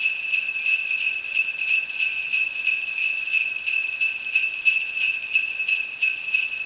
bal_sleighbells01.wav